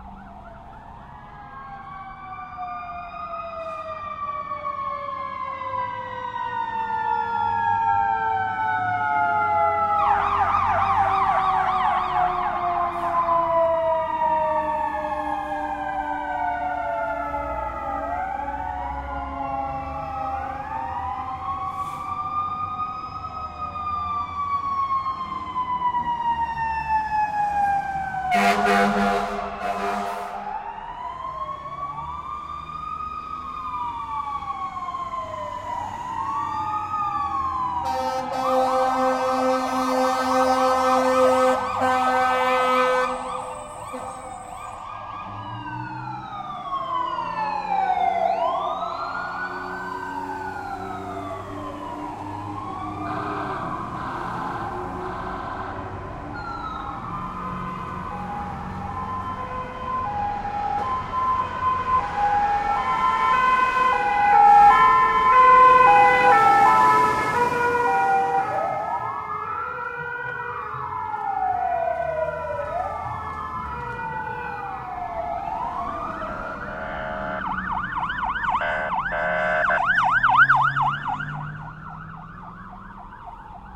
policeSirensLoop.ogg